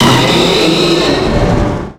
Cri de Méga-Braségali dans Pokémon X et Y.
Cri_0257_Méga_XY.ogg